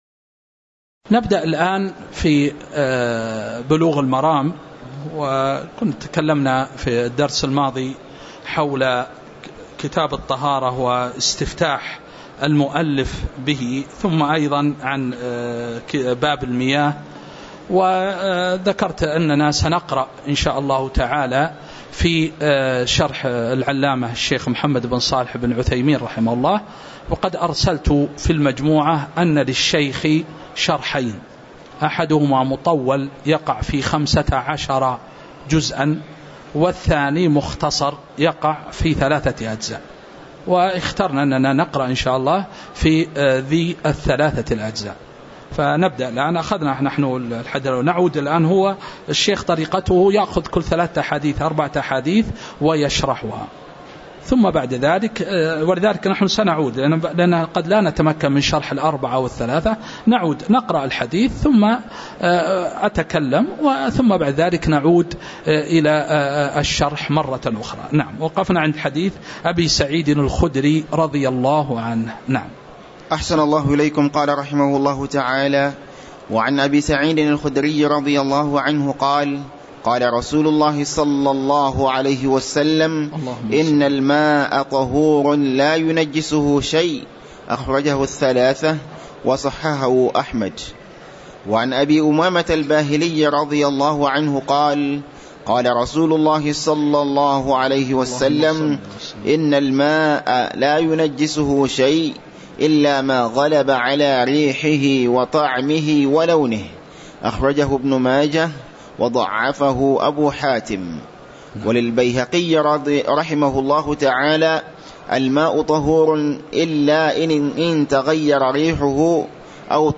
تاريخ النشر ٦ شوال ١٤٤٤ هـ المكان: المسجد النبوي الشيخ